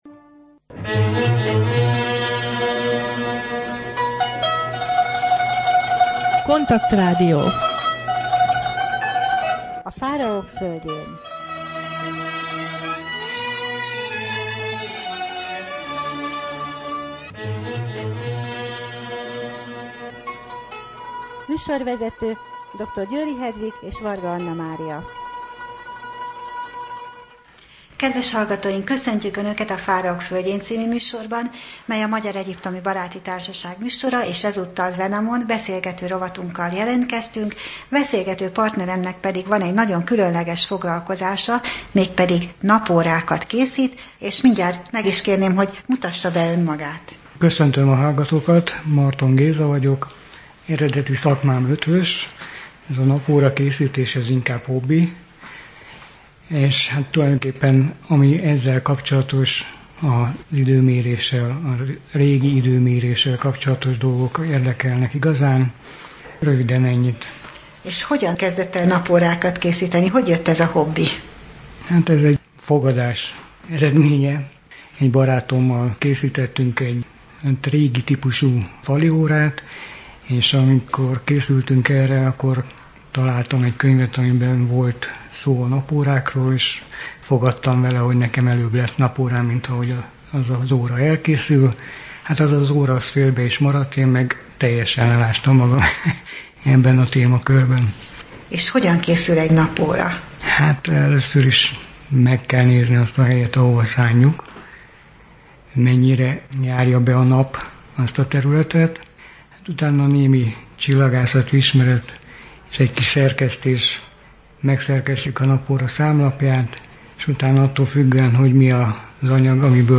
Wenamon beszélgető rovat